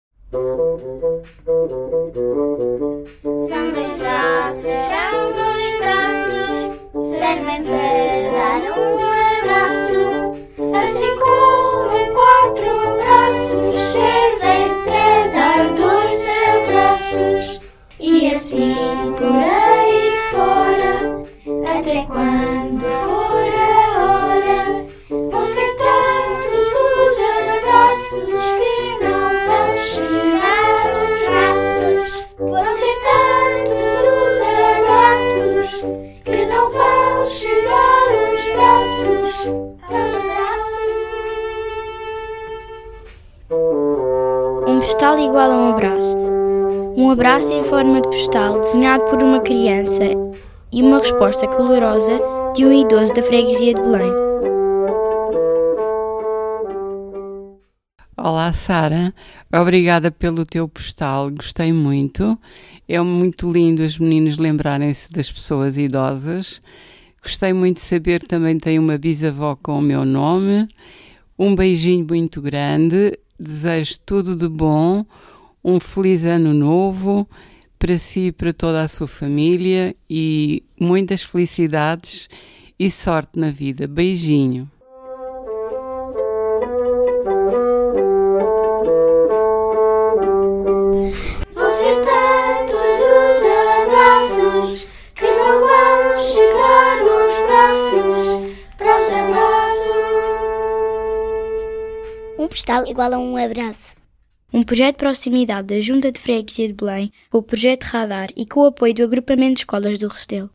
Um abraço em forma de postal desenhado por uma criança e uma resposta calorosa de um idoso da freguesia de Belém.
Oiça aqui as respostas dos idosos aos postais enviados pelos alunos.